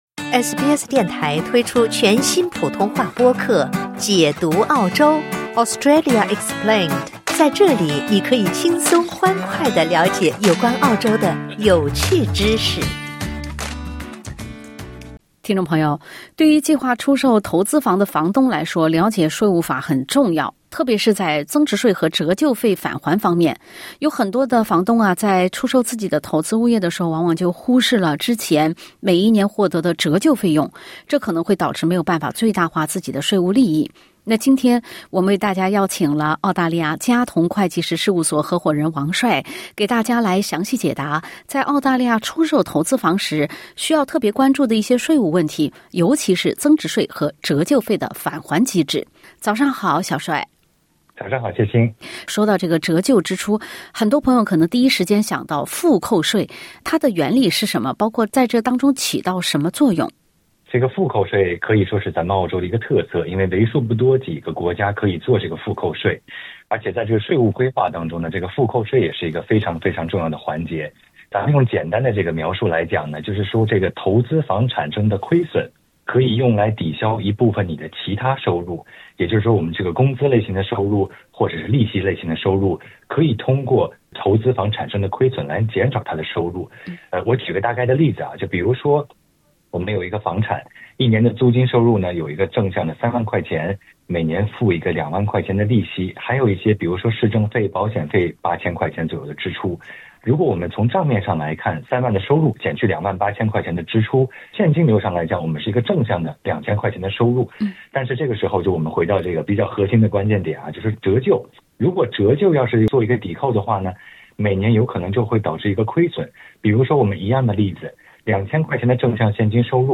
专业人士详细解答在澳大利亚出售投资房时需要特别关注的税务问题，尤其是增值税与折旧费的返还机制。